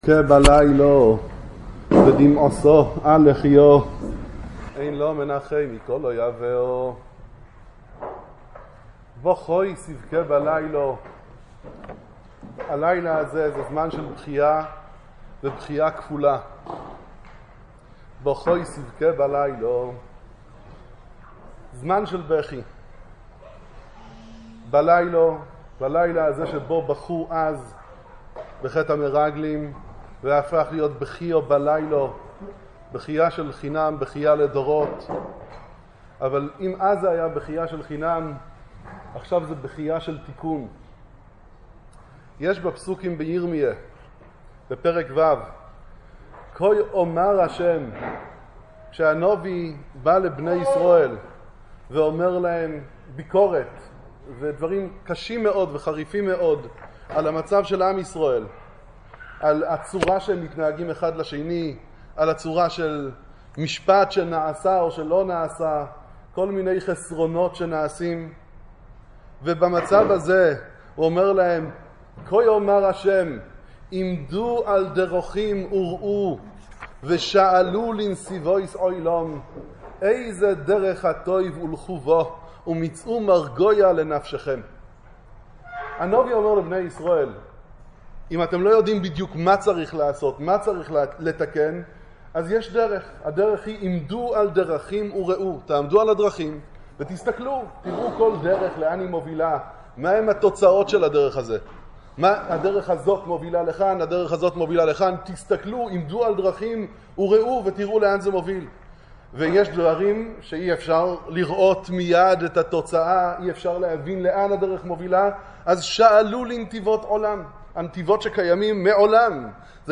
קינה ובכי ליל ט' באב